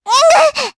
Luna-Vox_Damage_jp_01.wav